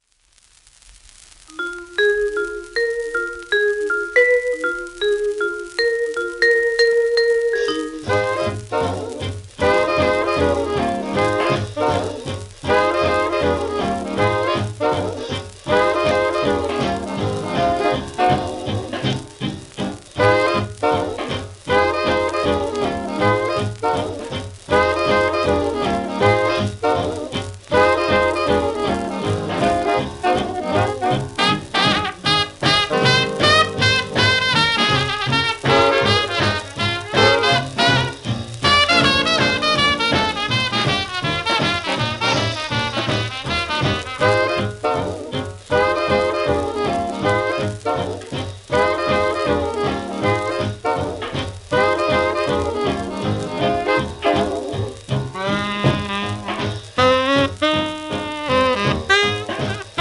w/オーケストラ
1940年録音
シェルマン アートワークスのSPレコード